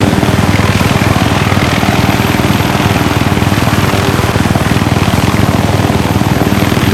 heli_engine.ogg